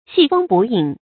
系風捕影 注音： ㄒㄧˋ ㄈㄥ ㄅㄨˇ ㄧㄥˇ 讀音讀法： 意思解釋： 拴住風，捉住影子。